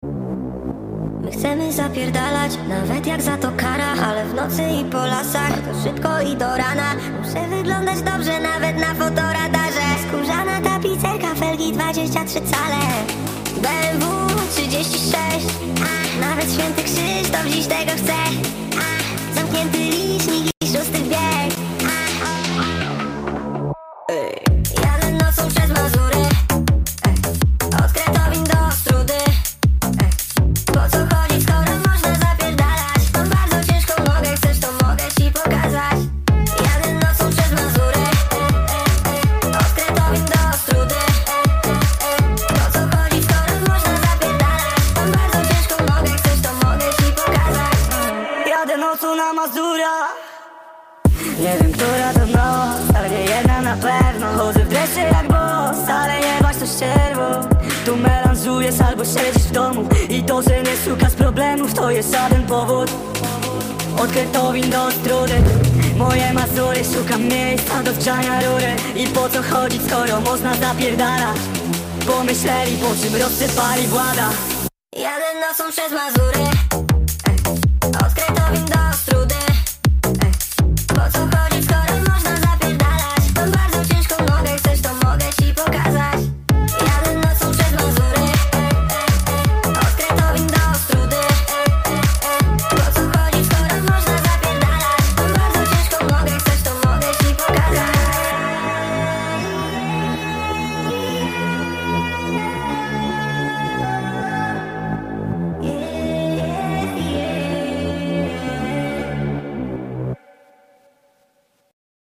(speed up)